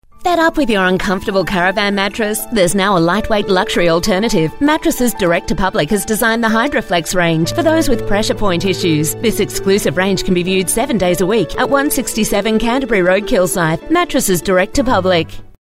3AW RadioRadio Ad